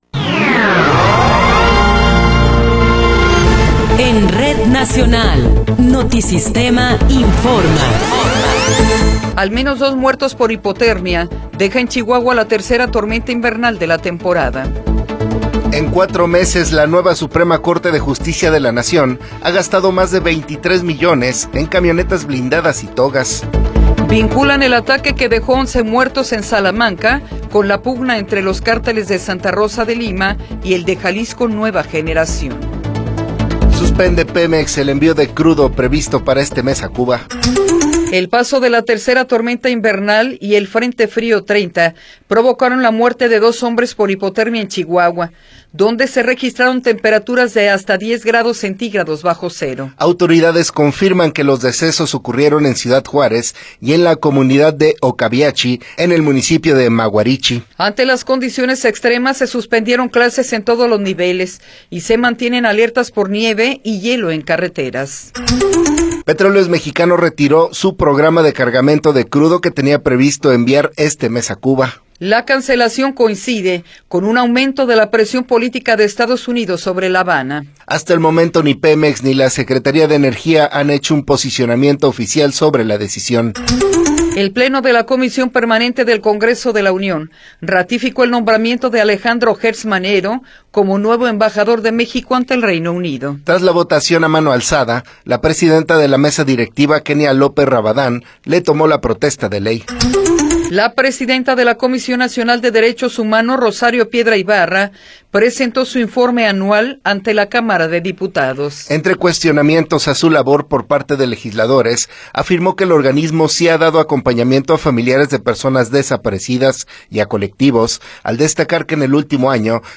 Noticiero 8 hrs. – 27 de Enero de 2026